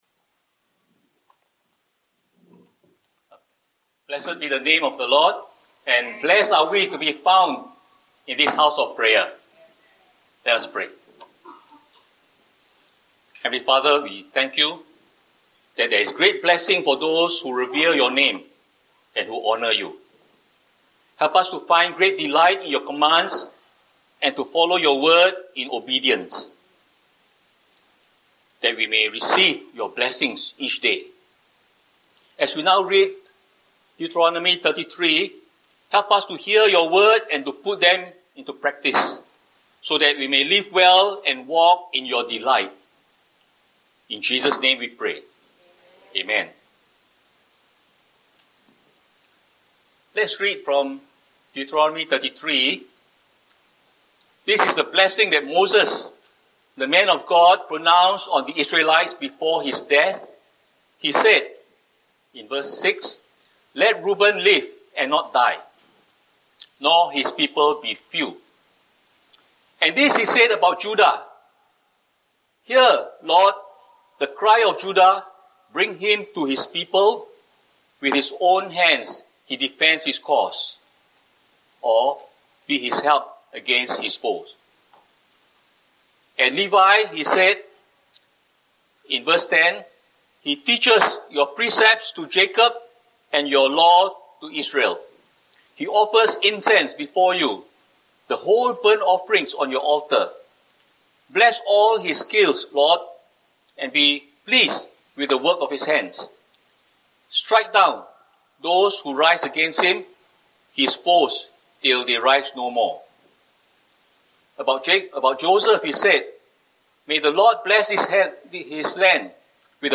Otherwise just click, the video / audio sermon will just stream right to you without download.